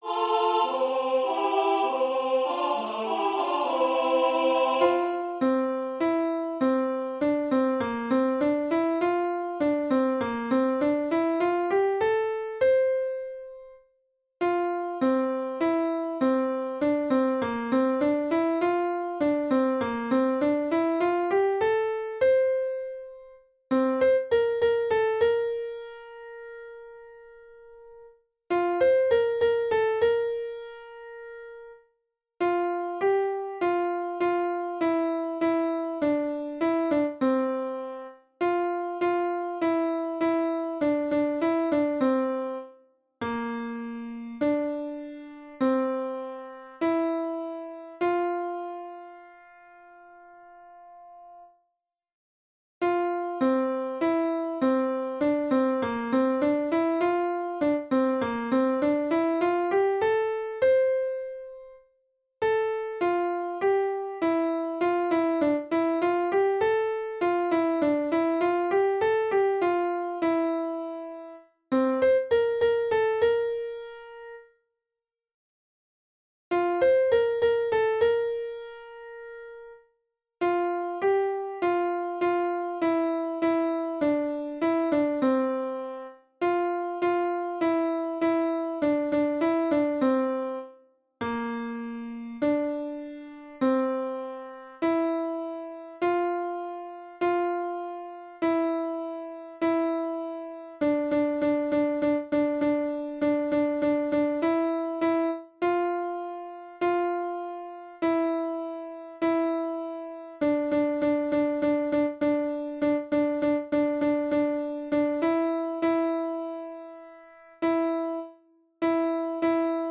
Choir